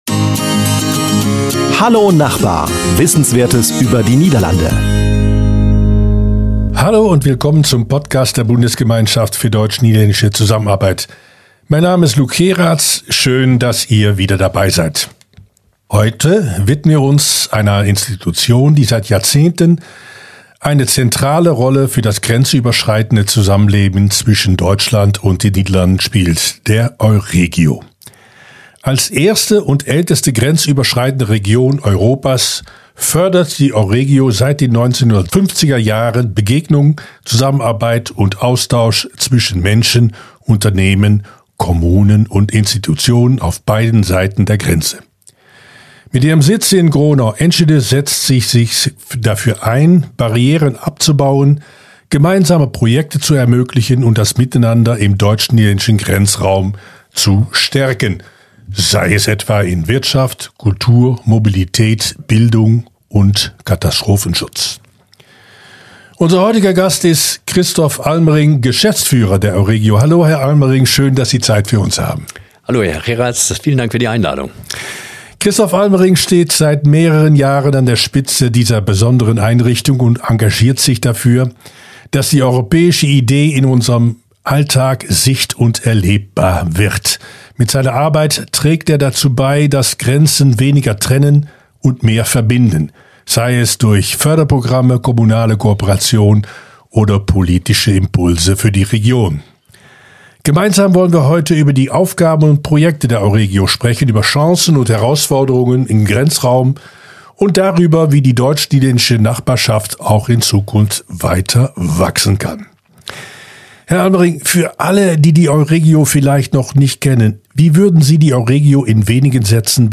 Beschreibung vor 4 Monaten Als erste und älteste grenzüberschreitende Region Europas fördert die EUREGIO seit den 1950er-Jahren Begegnung, Zusammenarbeit und Austausch zwischen Menschen, Unternehmen, Kommunen und Institutionen auf beiden Seiten der deutsch-niederländischen Grenze. In einem Interview